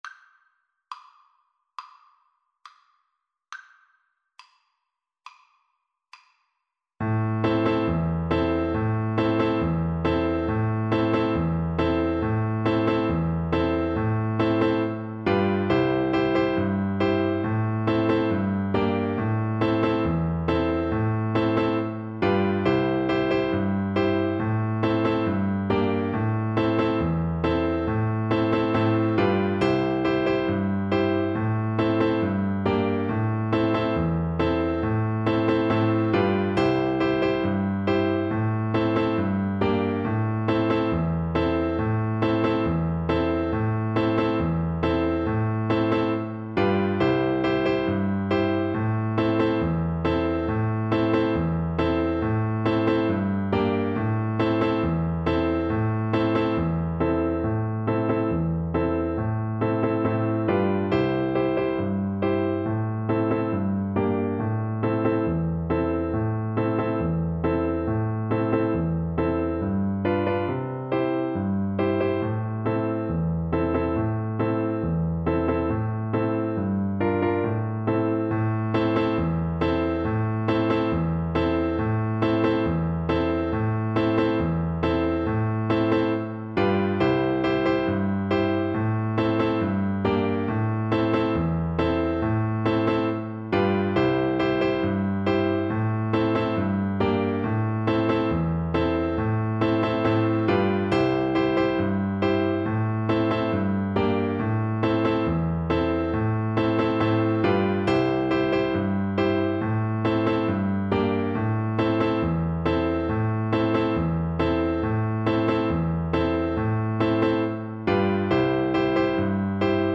Violin
D minor (Sounding Pitch) (View more D minor Music for Violin )
Moderato
4/4 (View more 4/4 Music)
World (View more World Violin Music)